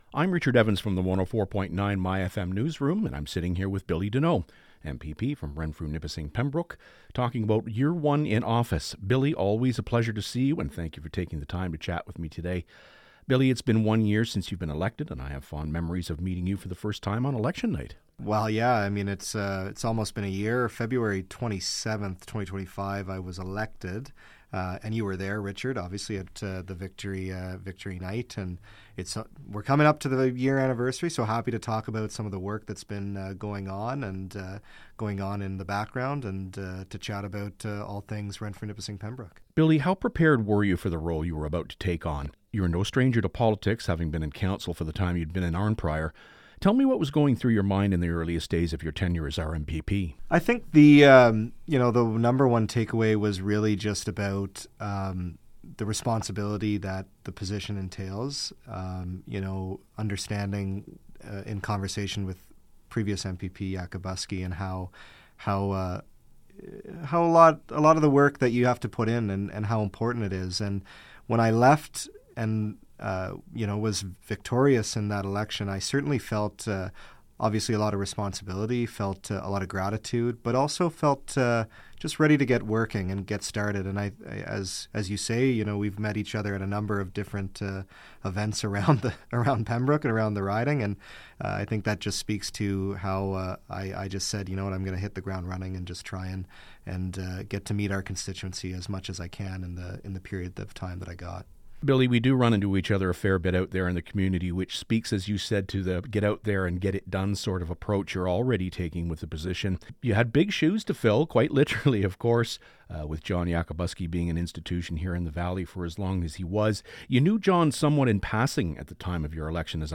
Coming up on the one year mark of Denault’s election, he sat down with me at the myFM studios to talk about some of the highlights and insights of his first year in office.